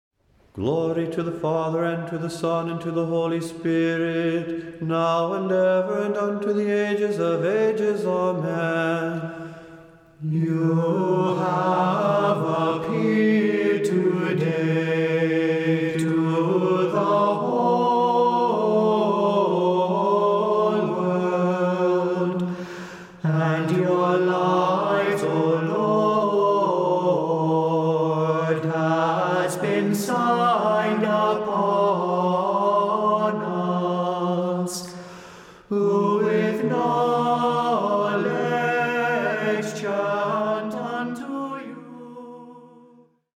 Melody: "Today the Virgin..."